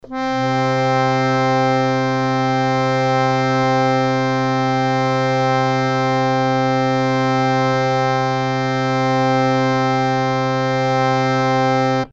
harmonium
C3.mp3